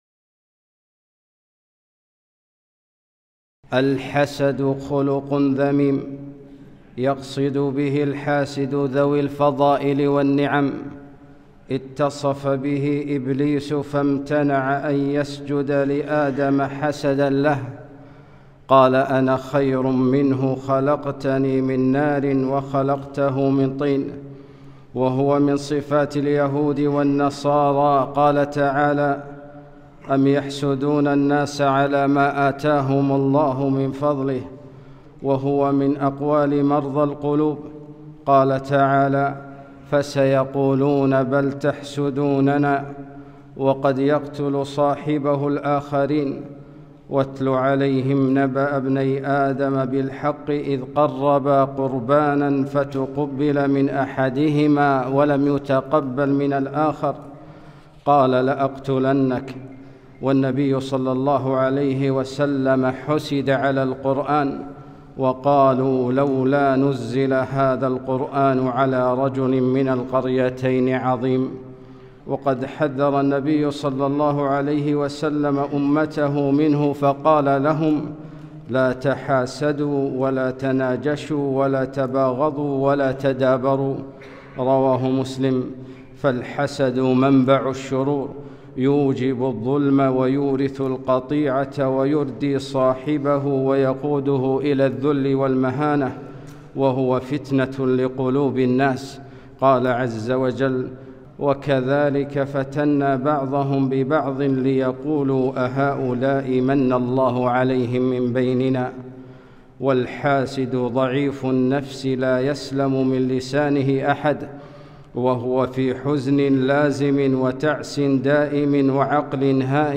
خطبة - الحسد